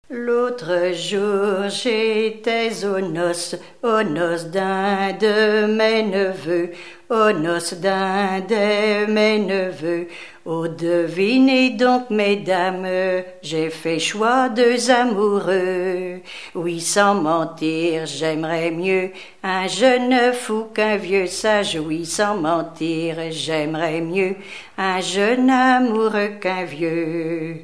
Chansons en dansant
Genre laisse
Pièce musicale inédite